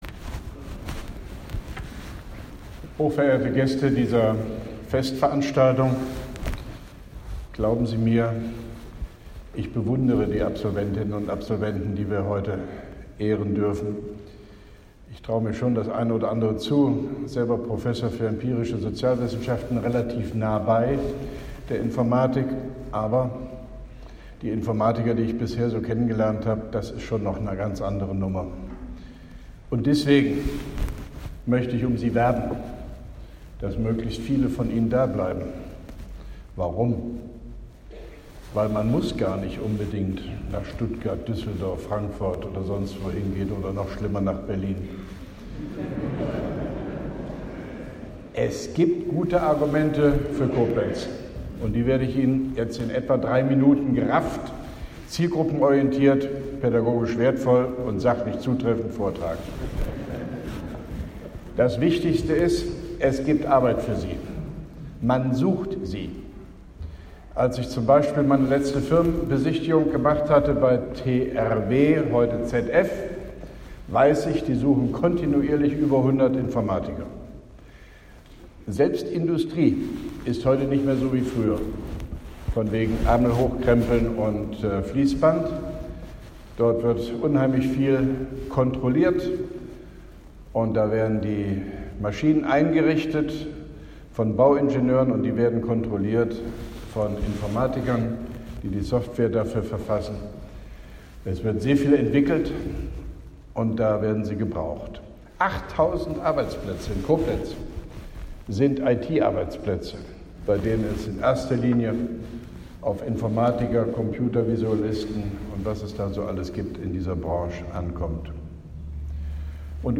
Grußwort von OB Hofmann-Göttig bei der Absolventen-Abschlussfeier des FB-4 (Informatik) der Universität Koblenz-Landau, Koblenz 24.11.2017